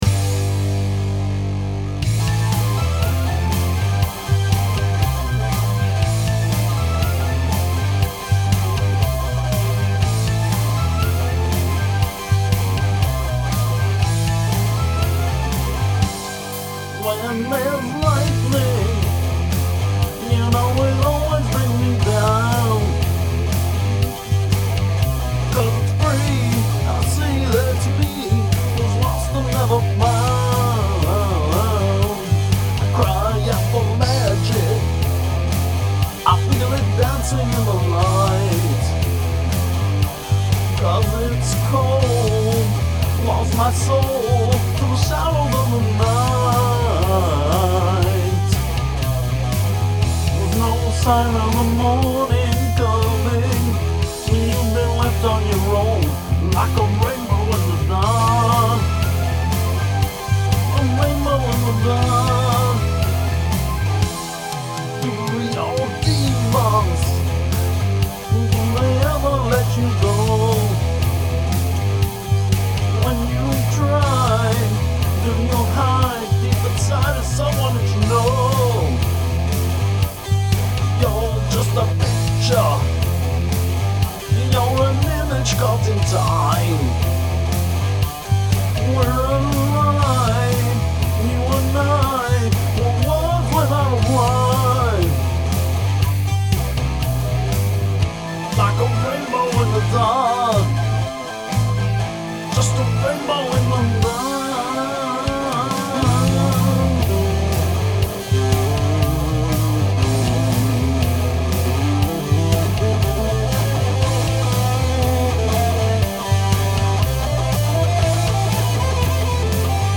heavy metal
The bridge and chorus have changed from the original.